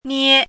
chinese-voice - 汉字语音库
nie1.mp3